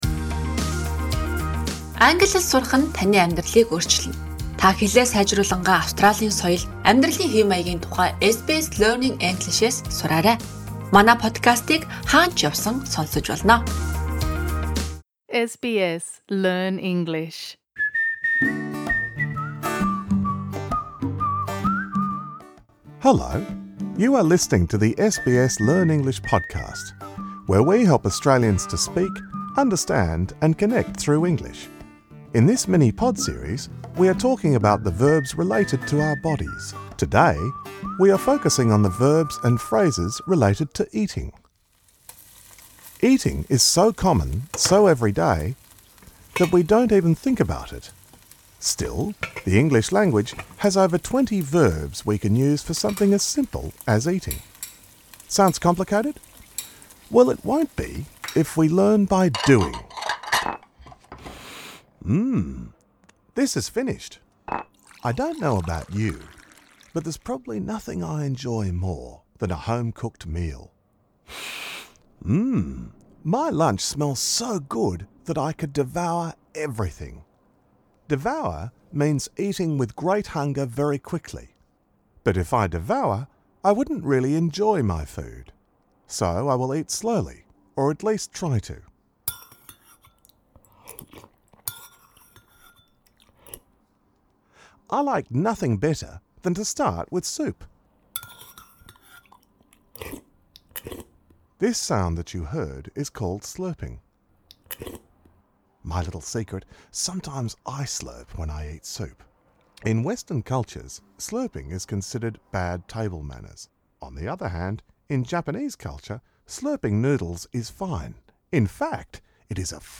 This lesson suits intermediate learners.